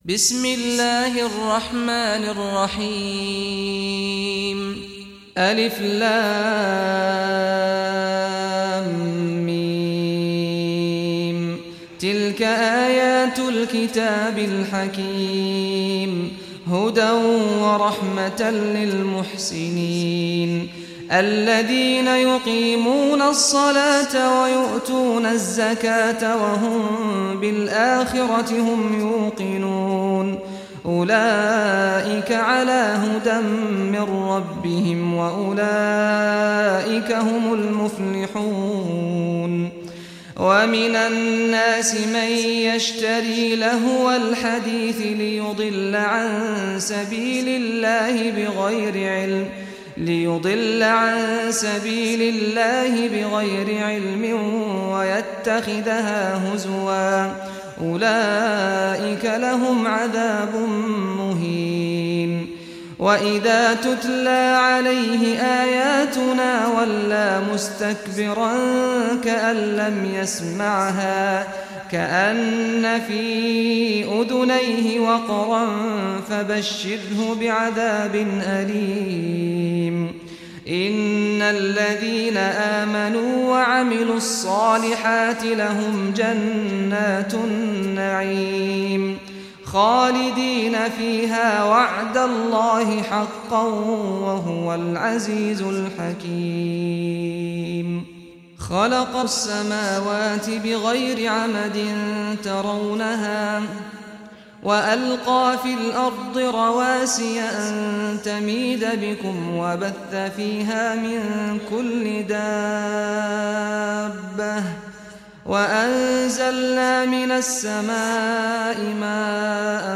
Surah Luqman Recitation by Sheikh Saad al Ghamdi
Surah Luqman, listen or play online mp3 tilawat / recitation in Arabic in the beautiful voice of Sheikh Saad al Ghamdi.